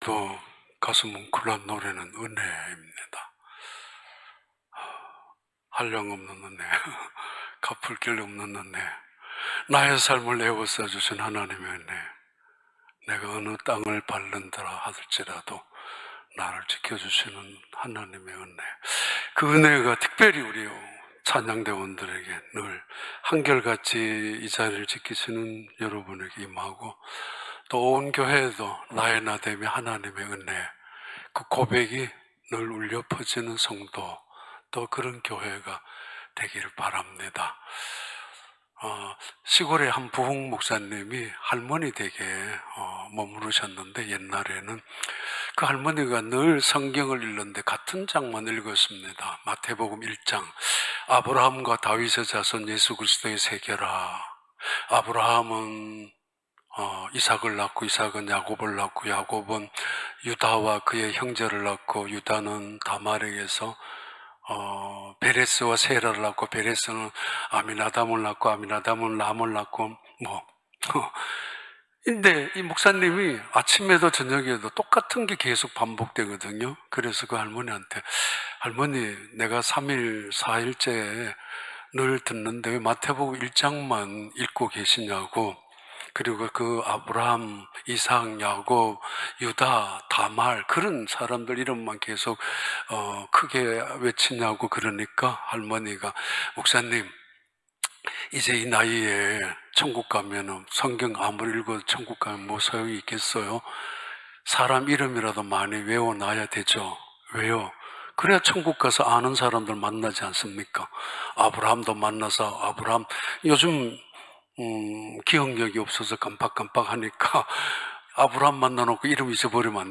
2021년 8월 15일 주일 3부 예배